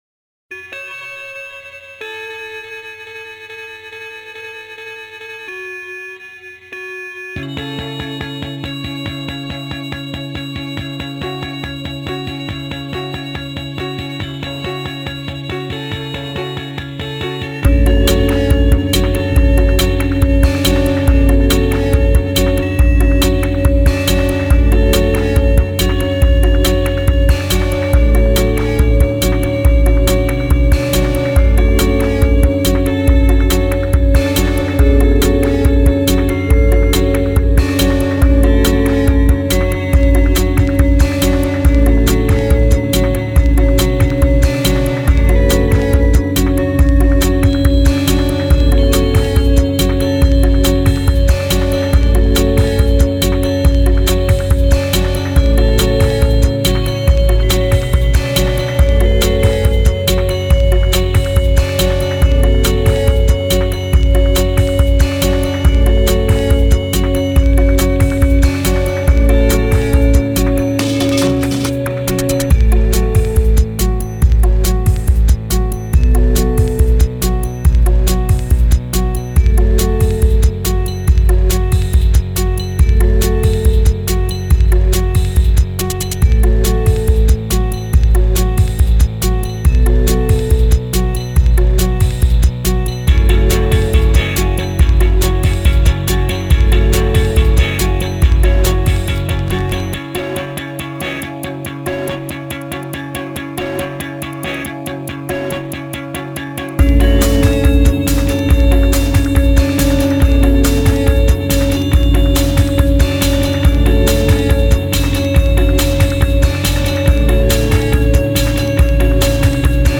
Genre: Sex Music.